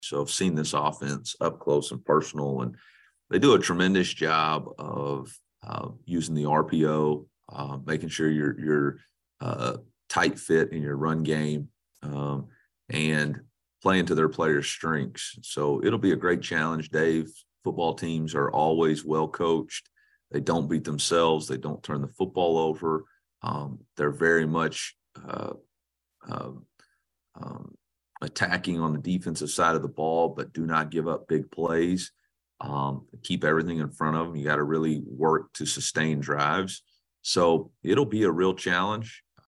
Mizzou football coach Eli Drinkwitz